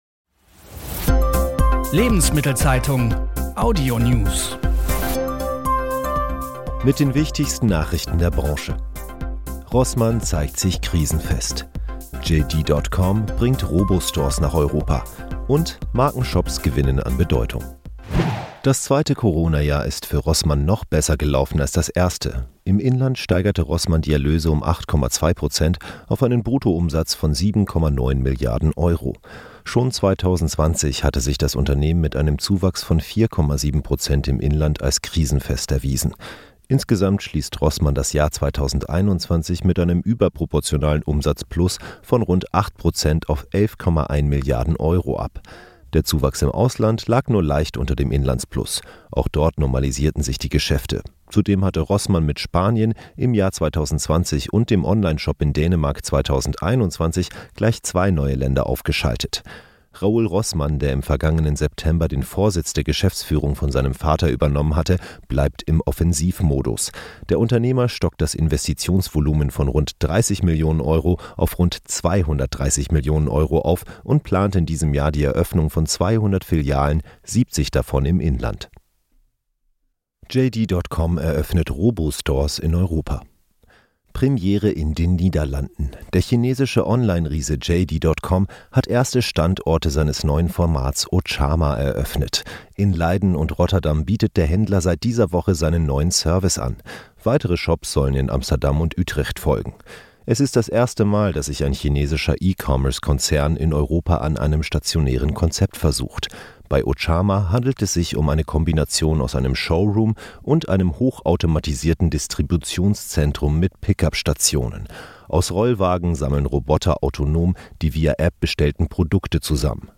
Wirtschaft , Nachrichten